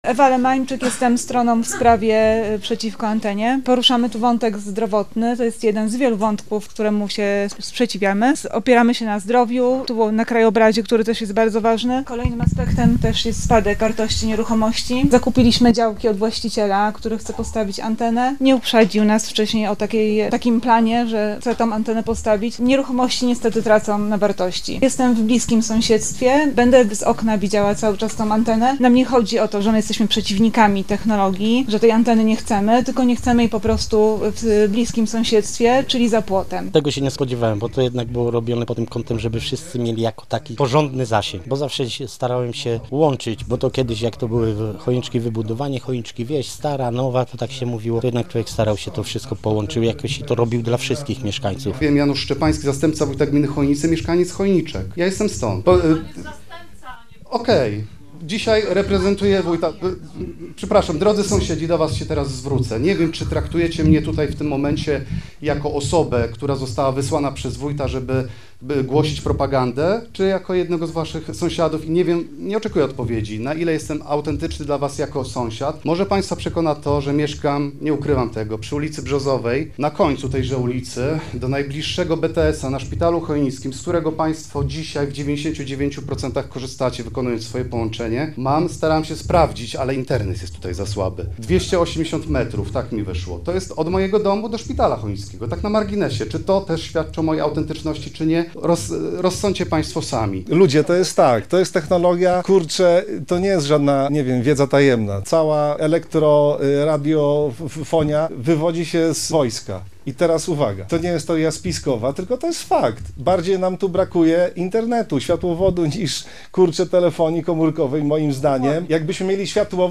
Posłuchaj materiału naszej reporterki: https